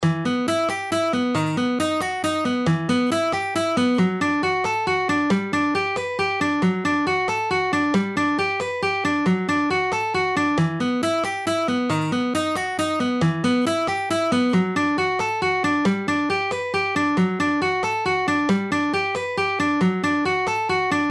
Exercises 1 in half speed:
Half-Speed-Alternate-Picking-Exercises-1-1.mp3